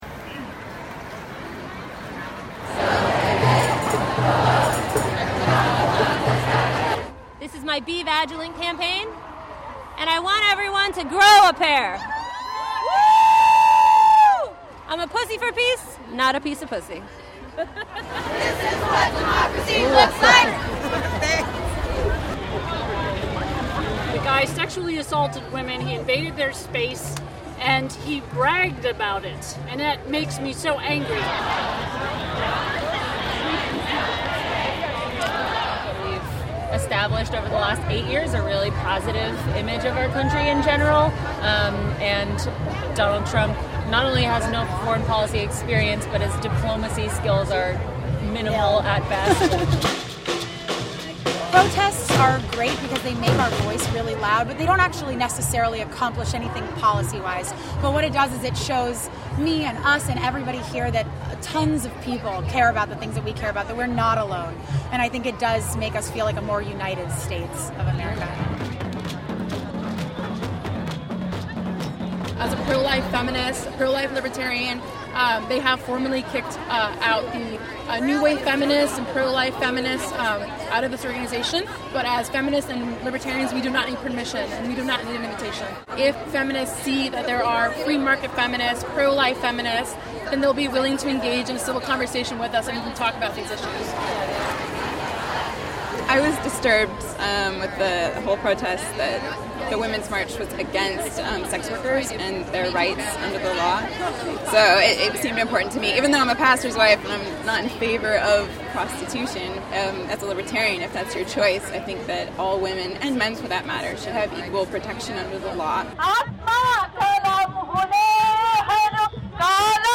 On January 21, an estimated 500,000 people attended the Women's March in Washington, D.C. as a protest against incoming President Donald Trump.
Reason TV spoke with protesters to find out what they fear the most about a Trump presidency and what they hope their rally will accomplish. We also talked with members of the Ladies of Liberty Alliance (LOLA), a feminist group that approaches gender issues from a libertarian perspective.